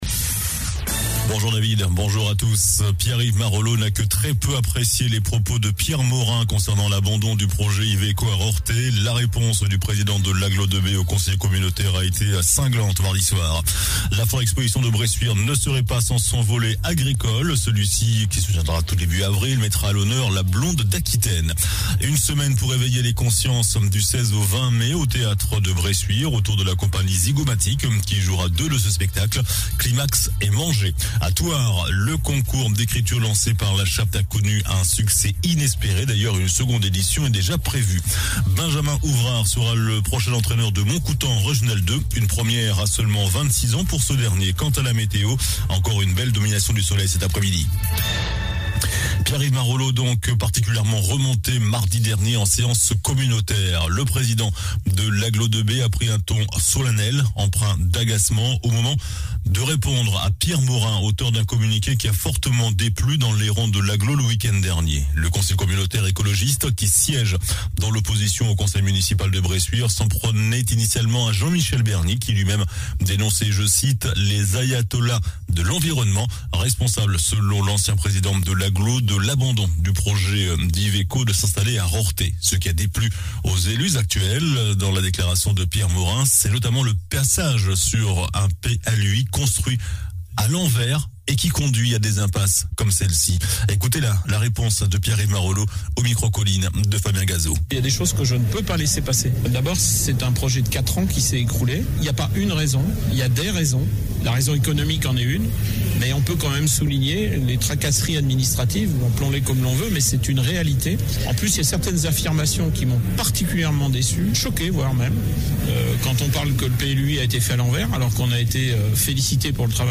JOURNAL DU JEUDI 24 MARS ( MIDI )